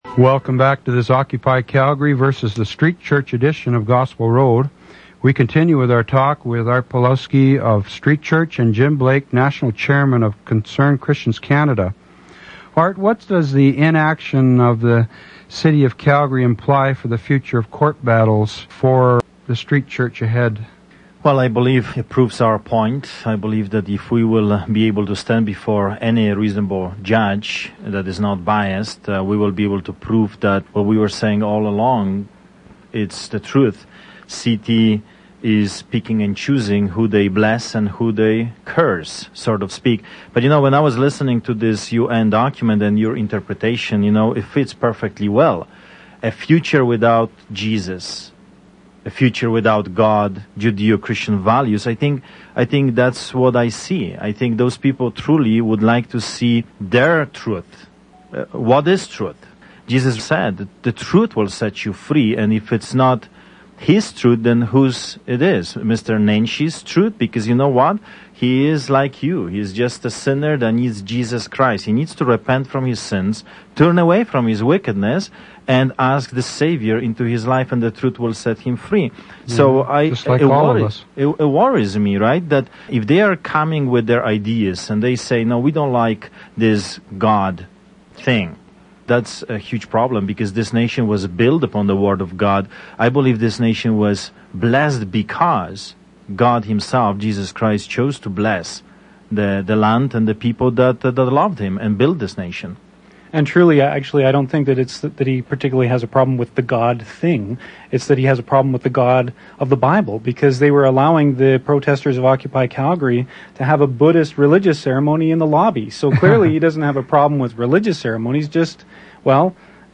on-air discussion